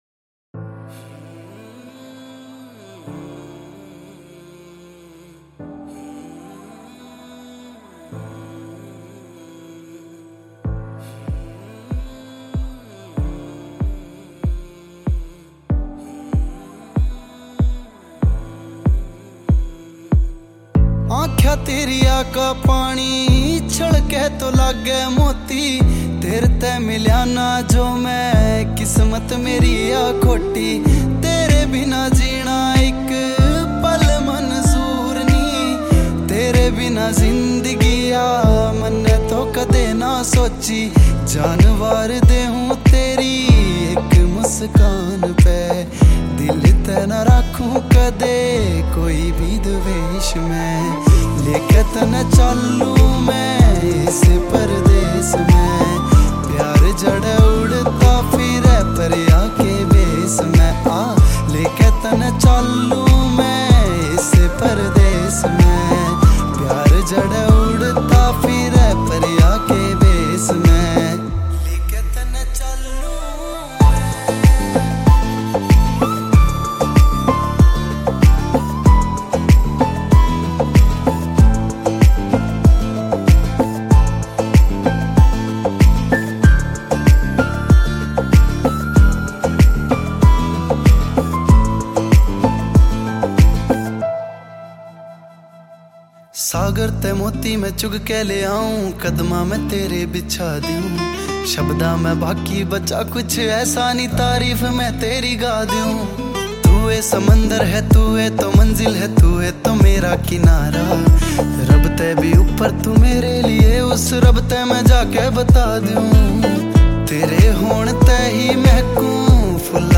Haryanvi